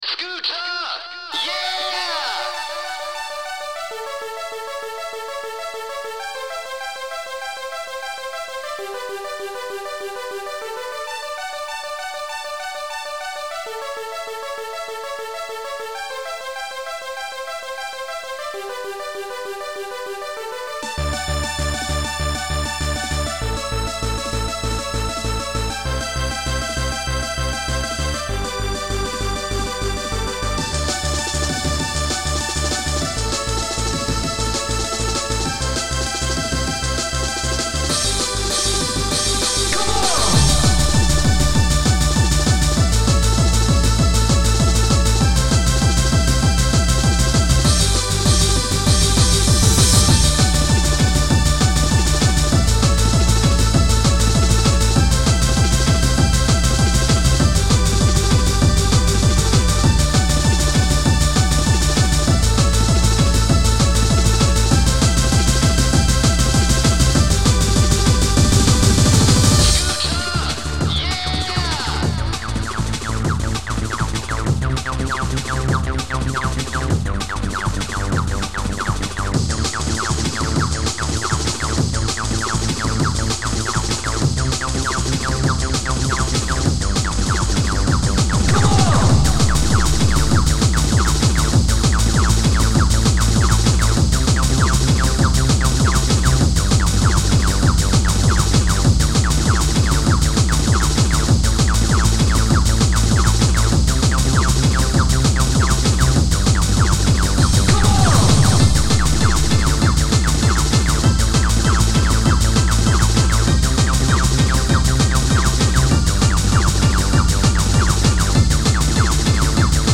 remix
• Jakość: 44kHz, Stereo